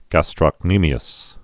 (găstrŏk-nēmē-əs, găstrə-)